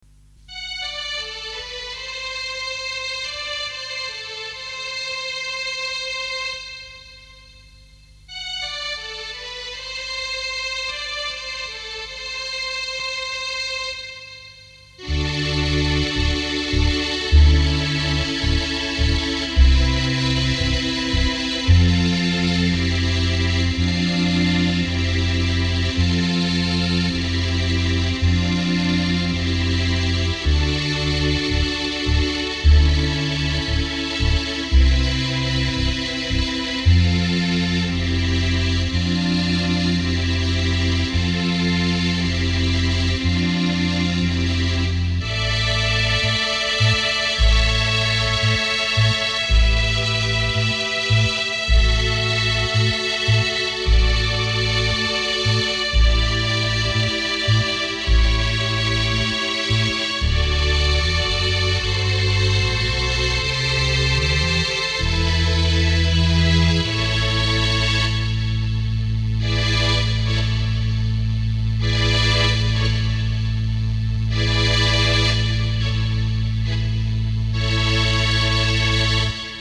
Das berühmte Hohner "Logan" String Melody